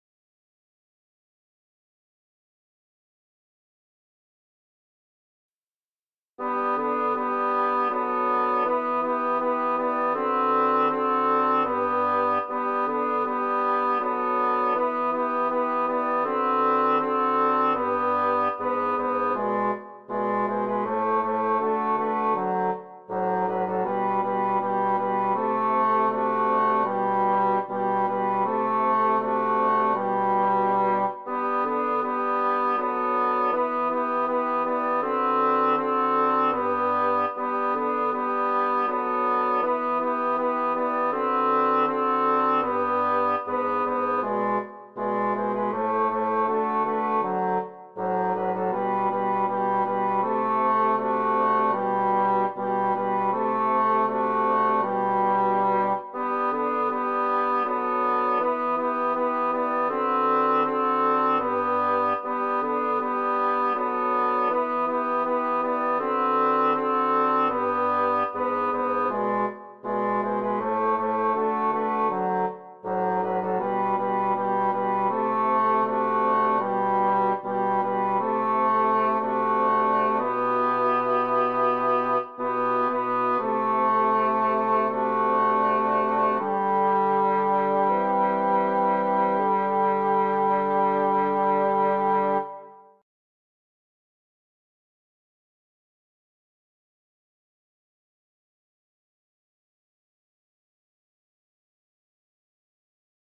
MP3 version instrumentale (les audios sont téléchargeables)
Tenor